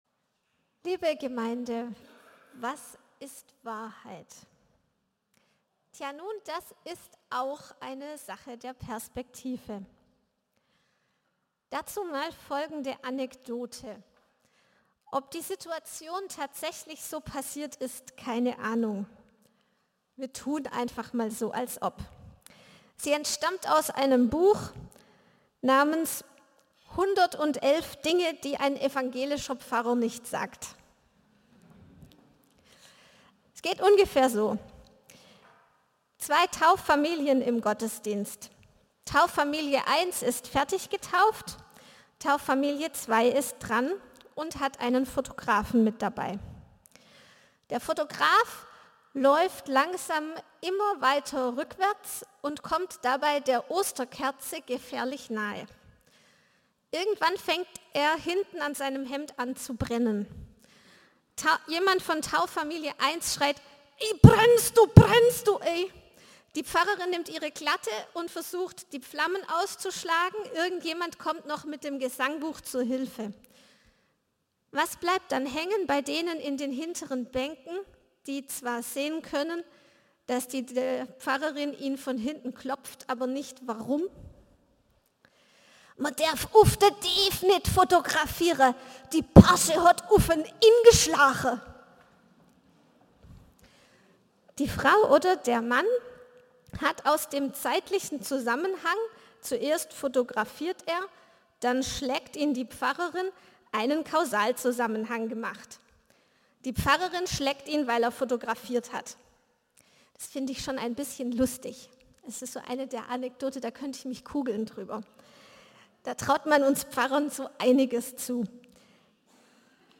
Teil 4 vor 4 Tagen 22.37 MB Podcast Podcaster eAg PredigtCast Predigten aus einANDERERGottesdienst Religion & Spiritualität Folgen 0 Podcast aneignen Beschreibung vor 4 Tagen Mehr Weitere Episoden Was ist Wahrheit?